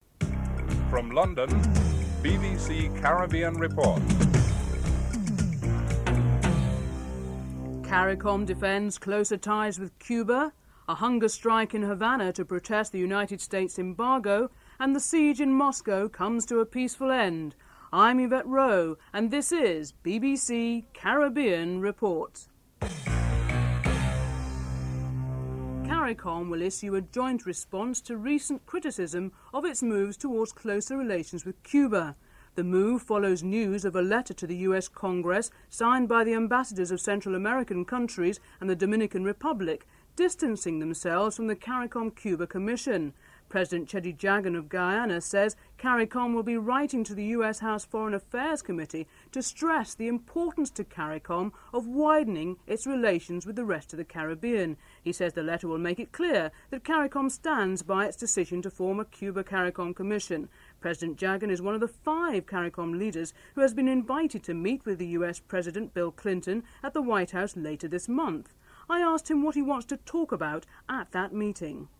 1. Headlines (00:00-00:24)
Interview with Rueben Meade, Chairman of OECS and Chief Minister of Monserrat (06:24-08:18)